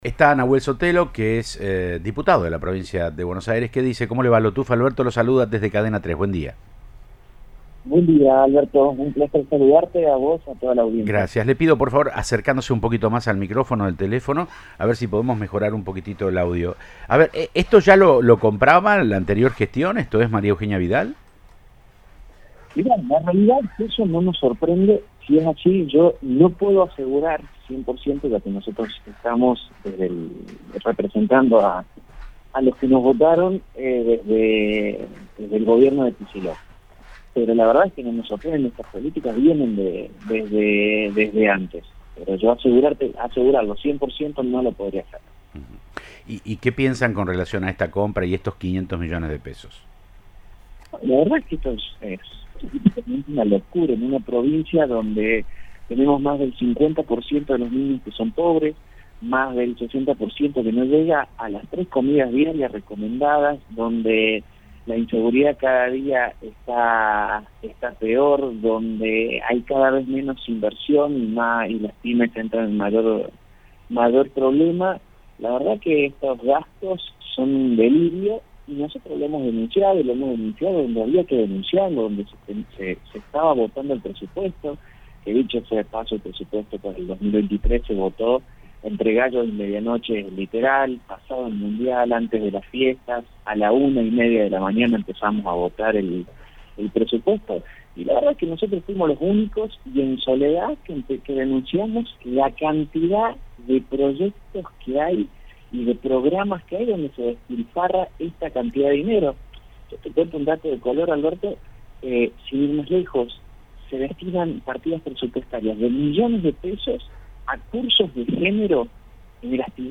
Nahuel Sotelo, Diputado de la Provincia de Buenos Aires en diálogo con Siempre Juntos de Cadena 3 Rosario dijo que es una locura en una provincia con 50% de niños pobres y la inseguridad cada día está peor y menos inversión en pymes.